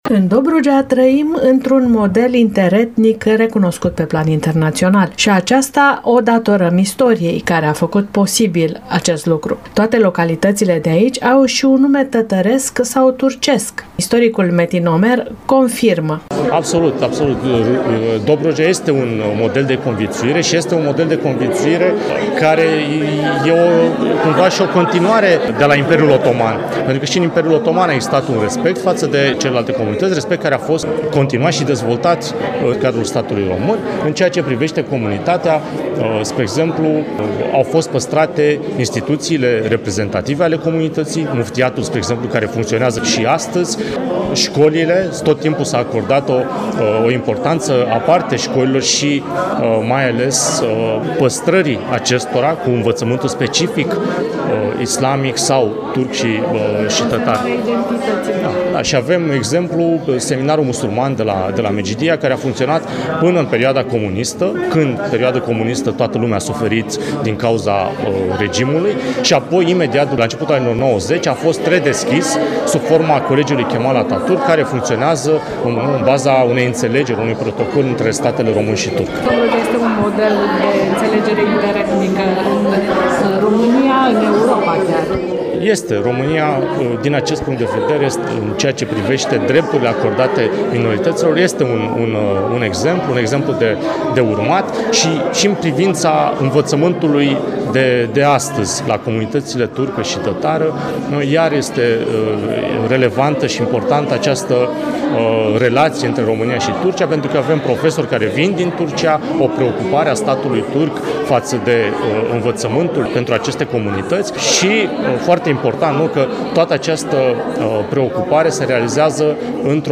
AUDIO | Ziua Minorităților Naționale. Interviu